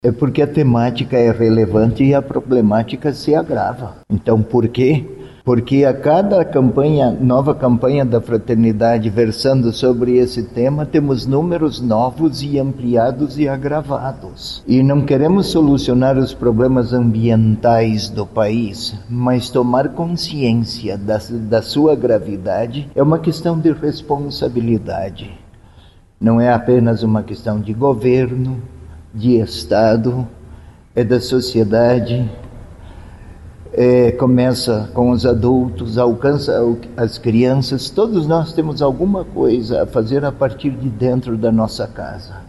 A divulgação deste ano aconteceu durante coletiva de imprensa, na Cúria Metropolitana, na capital.
O arcebispo metropolitano de Curitiba, Dom José Antônio Peruzzo, falou da necessidade do tema atual.